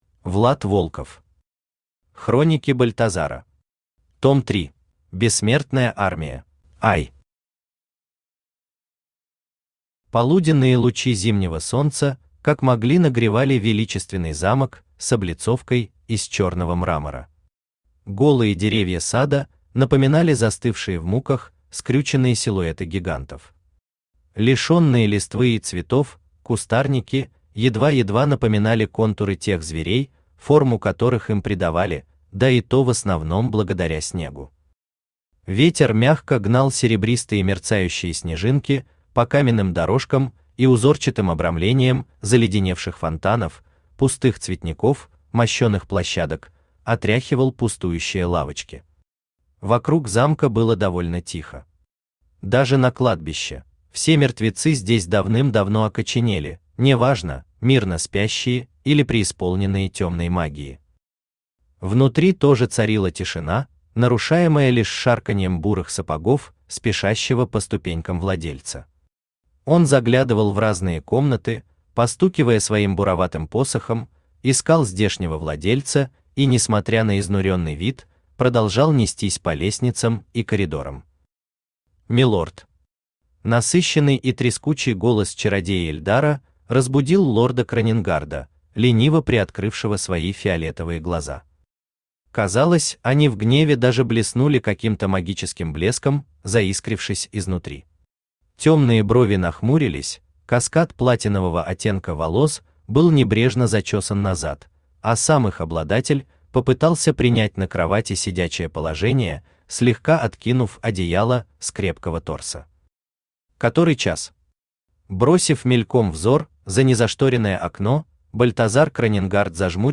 Том 3 Автор Влад Волков Читает аудиокнигу Авточтец ЛитРес.